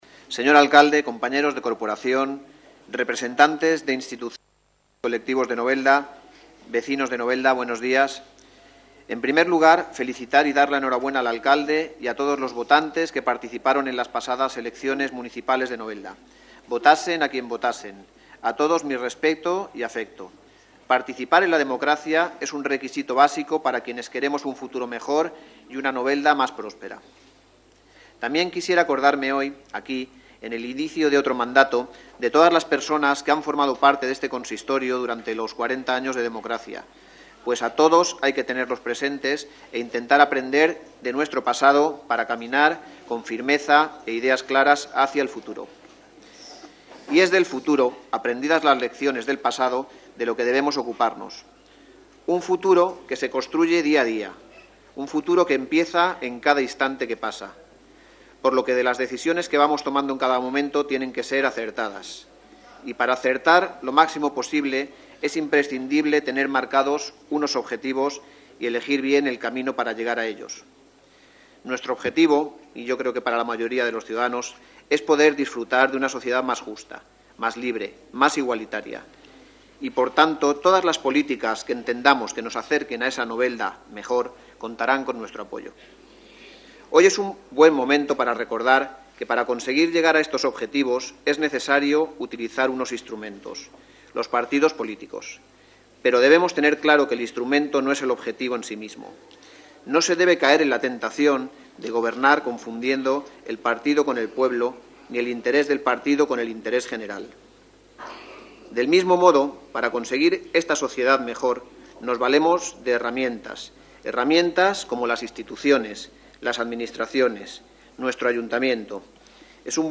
El pleno de constitución de la nueva corporación municipal y elección de alcalde ha contado con numeroso público que, tanto en el Salón de Plenos como en el vestíbulo del Ayuntamiento, ha podido seguir en directo una sesión en la que han estado presentes los tres alcaldes socialistas de la etapa democrática, Salvador Sánchez, Luis Gómez y Mariano Beltrá, así como la diputada autonómica Laura Soler.